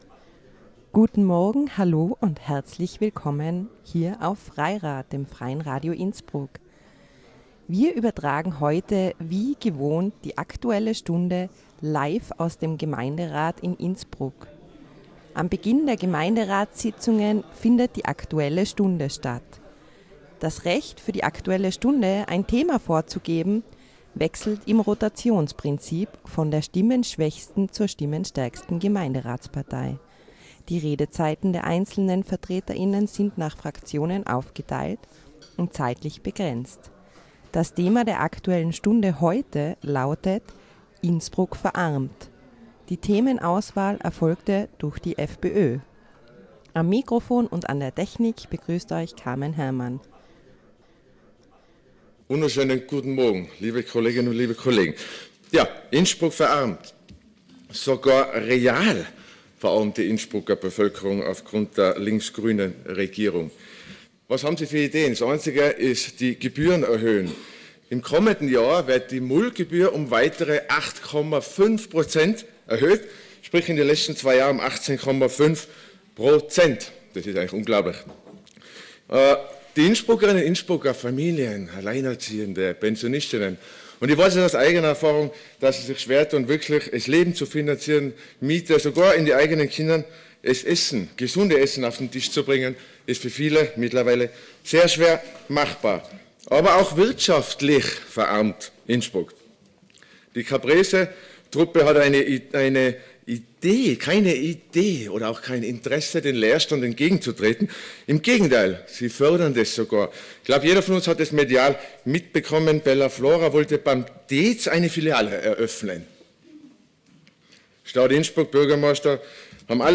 FREIRAD ist vor Ort und sendet die Aktuelle Stunde LIVE aus dem Innsbrucker Rathaus.